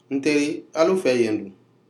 Dialogue: [nko]ߒߞߏ ߞߊ߲ ߝߐ[/nko]
This is a dialogue of people speaking Nko as their primary language.